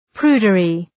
Προφορά
{‘pru:dərı}